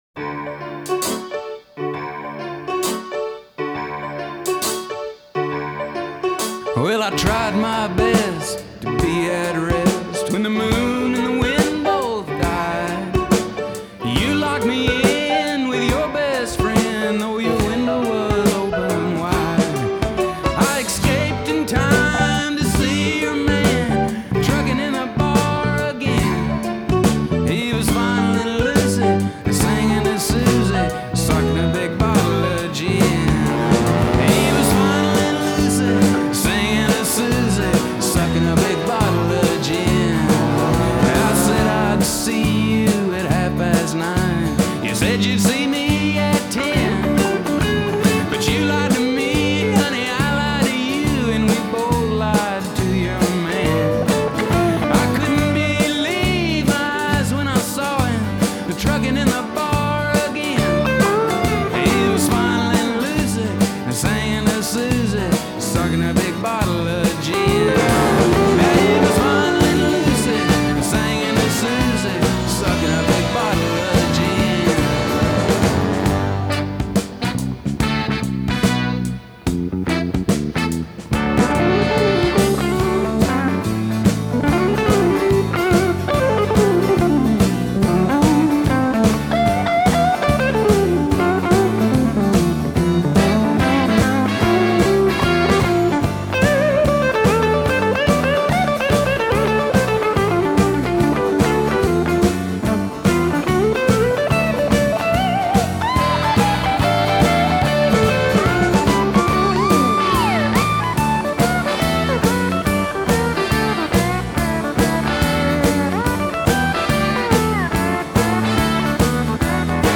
Rock 'N Roll sensibilities with hardcore Honky-Tonk